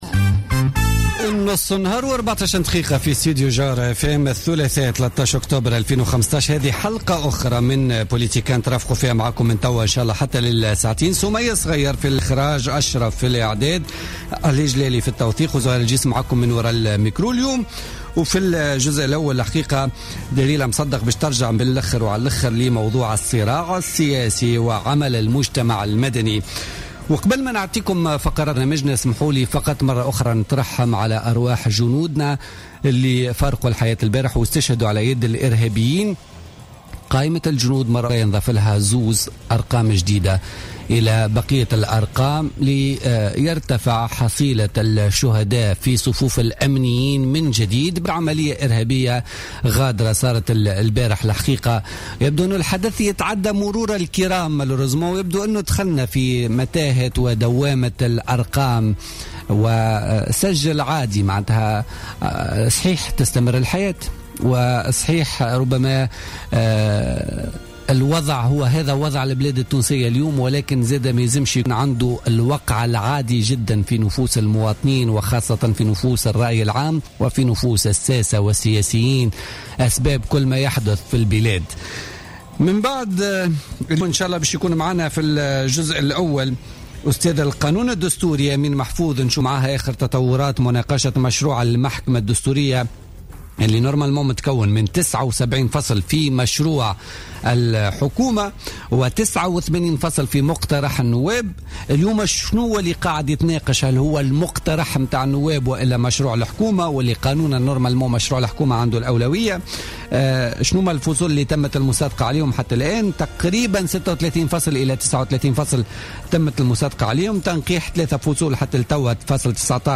Interview avec la députée et dirigeante d'Ennahdha, Meherzia Laâbidi